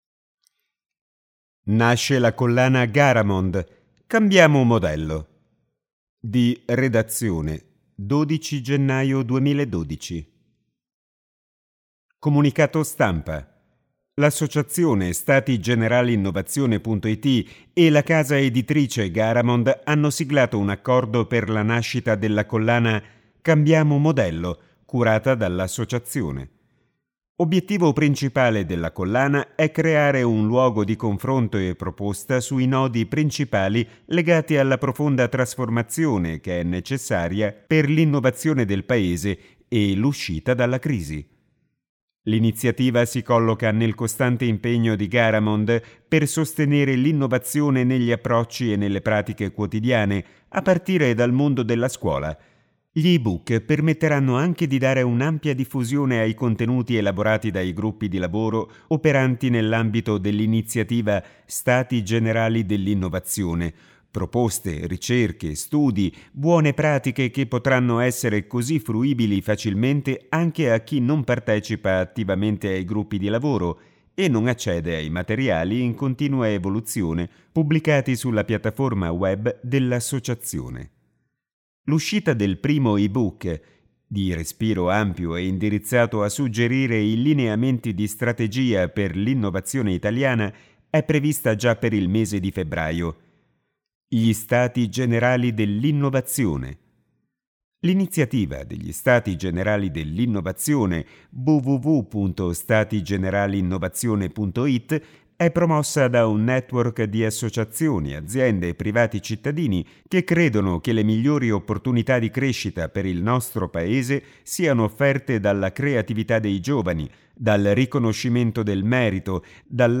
Comunicato Stampa